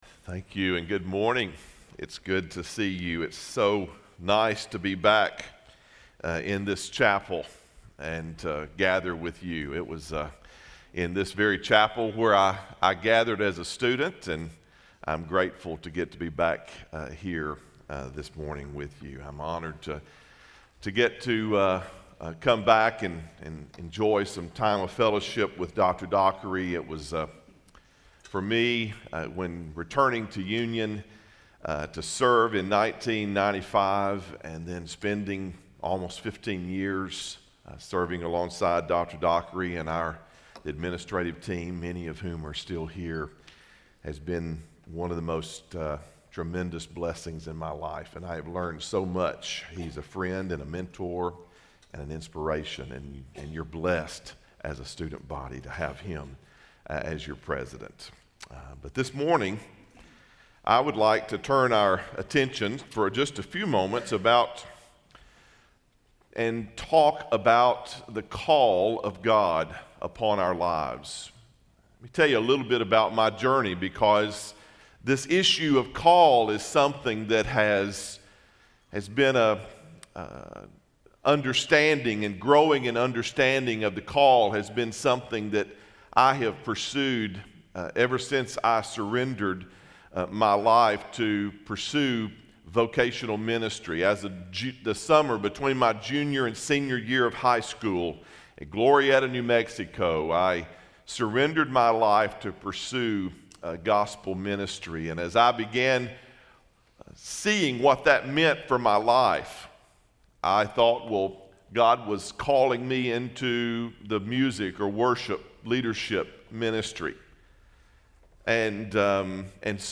The Union Audio Project | Union University, a Christian College in Tennessee